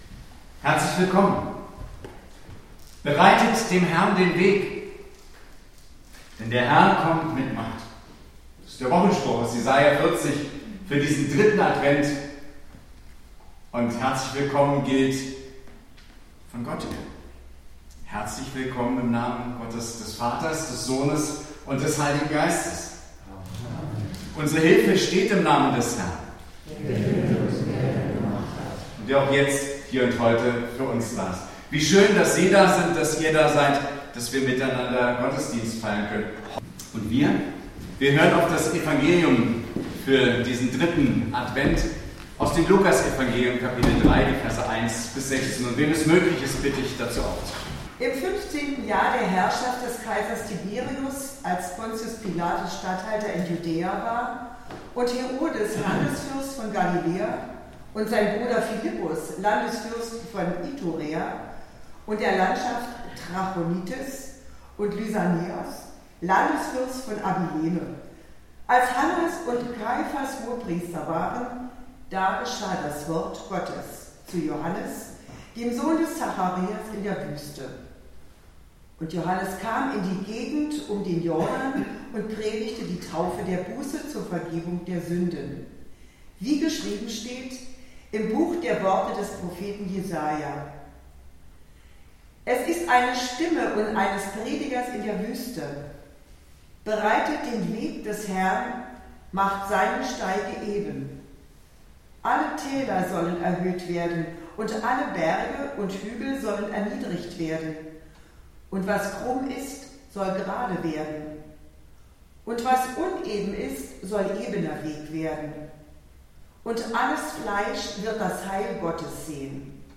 Predigt am 3. Advent - 14.12.2025